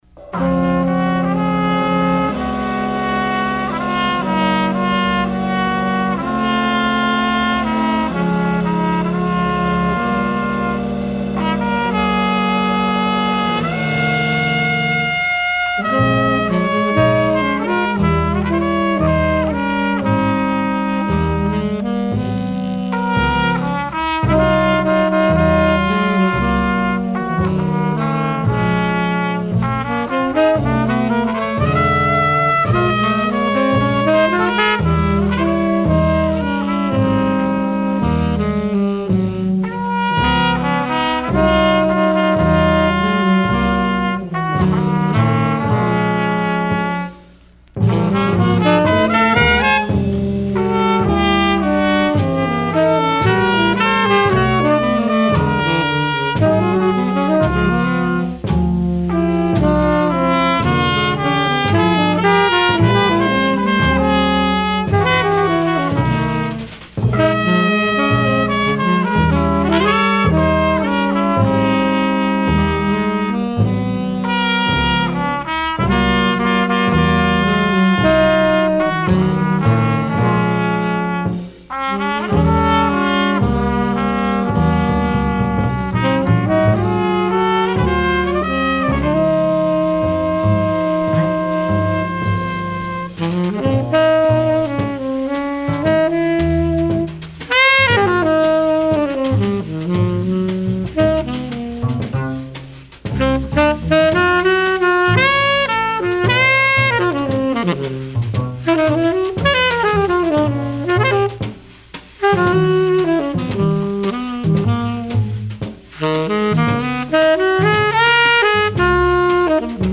Original 1958 mono pressing
Recorded September 4, 1958 at Reeves Sound Studios NYC